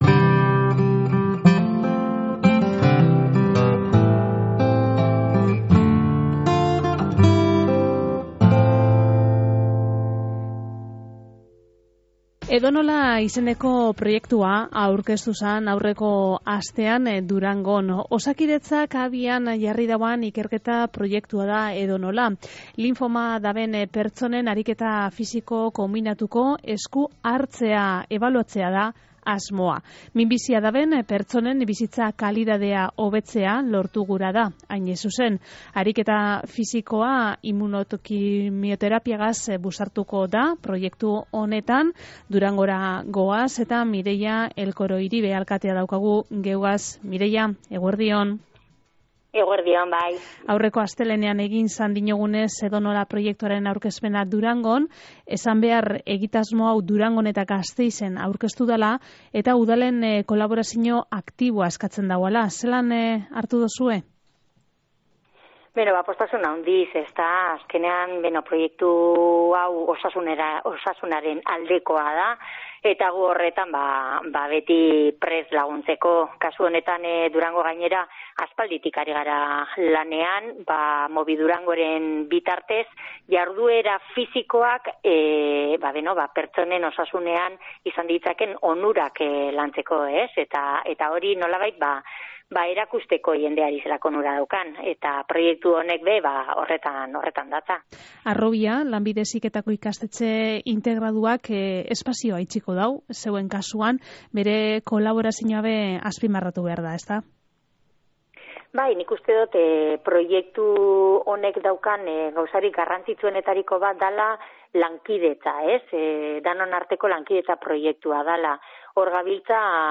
Mireia Elkoroiribe Durangoko alkateak Lau Haizetara irratsaioan azaldu dauanez, proiektuan parte hartuko daben pazienteek kirol-saio gidatuak jasoko dabez. Besteak beste, indarra, mugikortasuna edota entrenamendu aerobikoa landuko dabe.